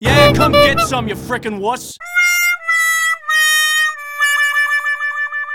die of death taunt Meme Sound Effect
die of death taunt.mp3